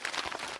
x_enchanting_scroll.6.ogg